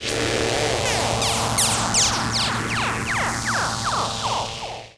Roland E Noises
Roland E Noise 18.wav